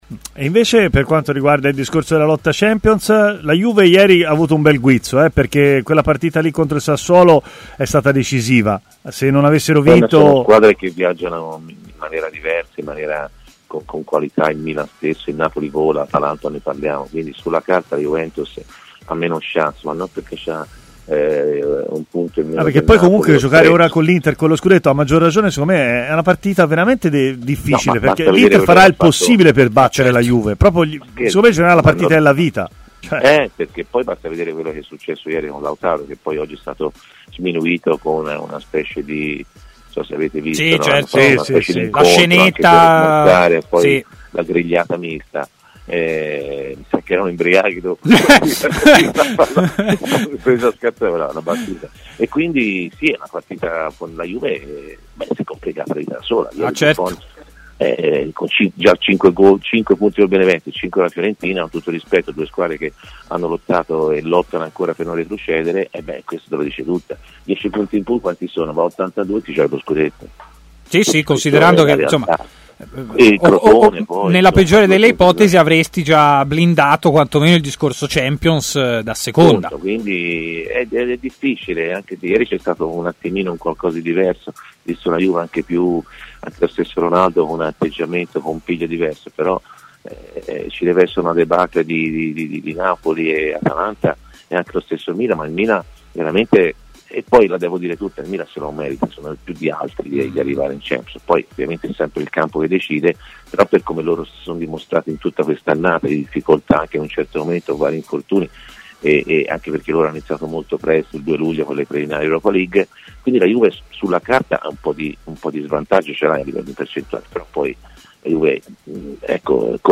Antonio Di Gennaro, parlando ai microfoni di TMW Radio, ha parlato di vari temi tra cui anche del match di sabato sera tra Juventus e Inter, ecco alcune delle sue parole: "L'Inter farà di tutto per battere la Juve, basta vedere quello che è successo ieri con Lautaro.